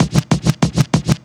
Night Rider - Scratch.wav